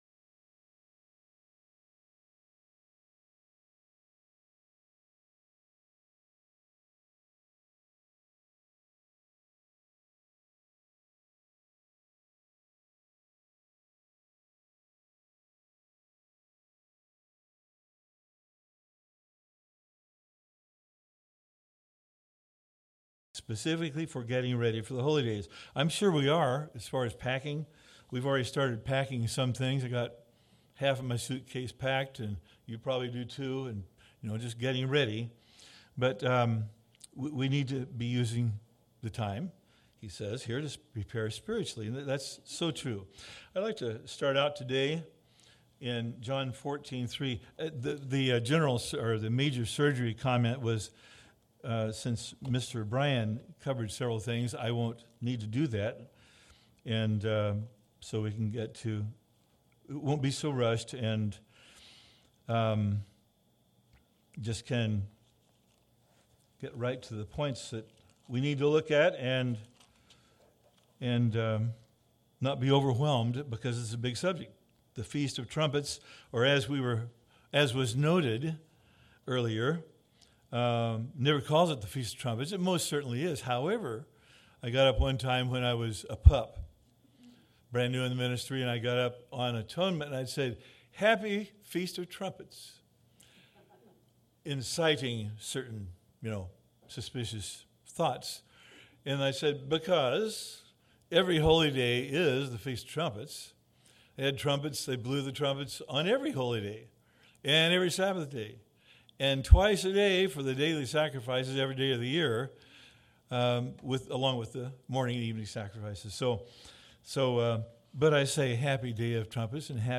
Please be aware there is about 20 seconds of dead air at the beginning of the recording that could not be edited out